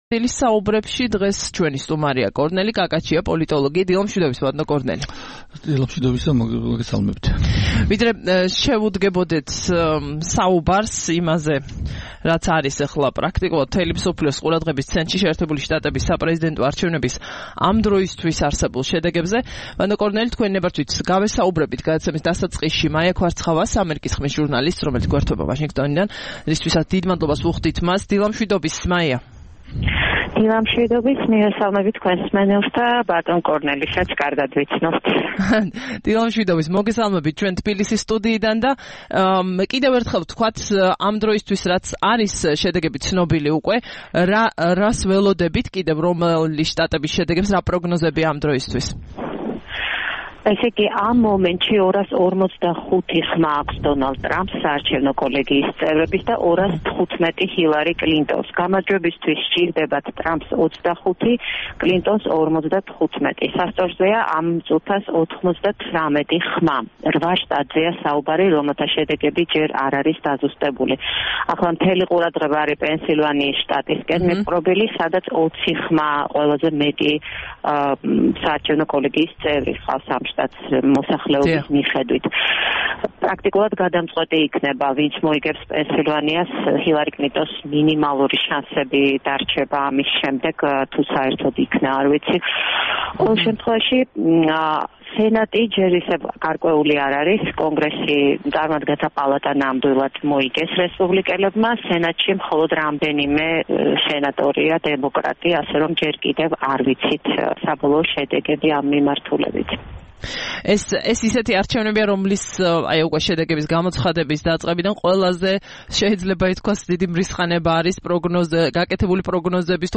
9 ნოემბერს რადიო თავისუფლების "დილის საუბრების" სტუმარი იყო